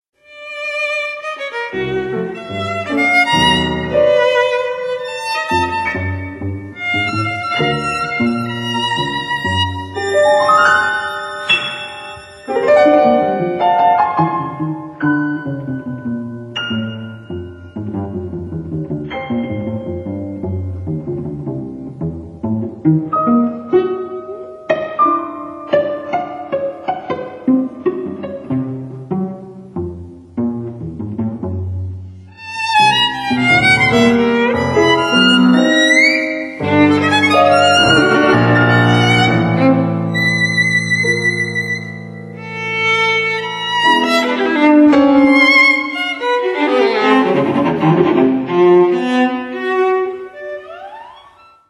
Klaviertrio 1984